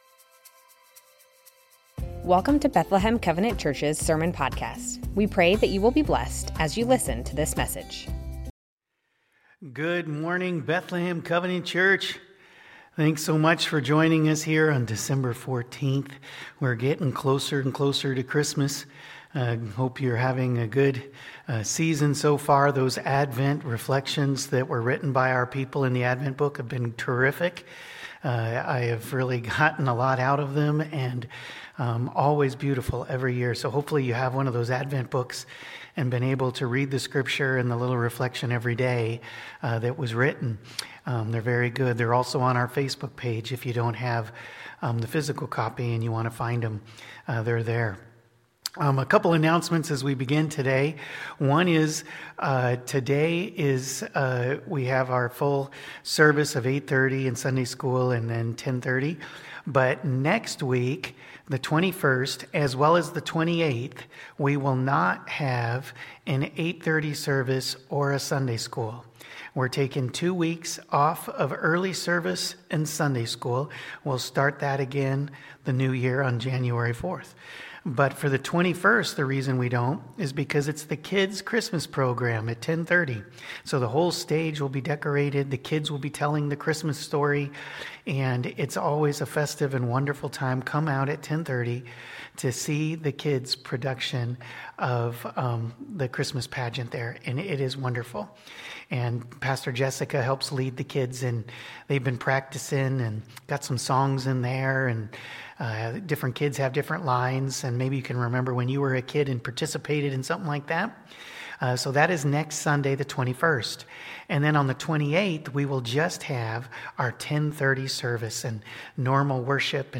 Bethlehem Covenant Church Sermons Advent - Joy Dec 14 2025 | 00:33:37 1x 00:00 / 00:33:37 Subscribe Share Spotify RSS Feed Share Link Embed